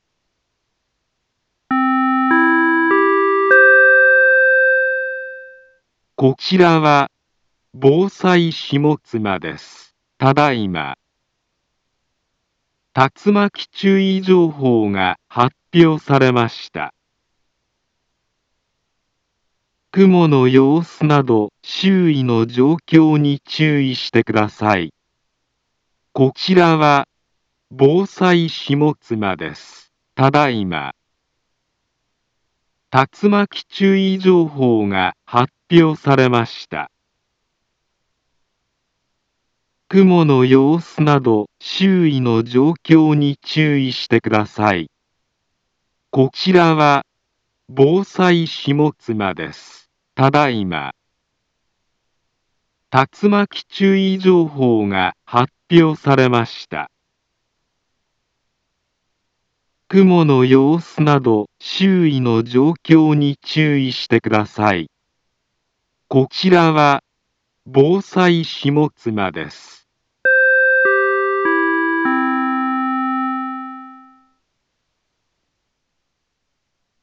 Back Home Ｊアラート情報 音声放送 再生 災害情報 カテゴリ：J-ALERT 登録日時：2022-06-27 17:39:52 インフォメーション：茨城県北部、南部は、竜巻などの激しい突風が発生しやすい気象状況になっています。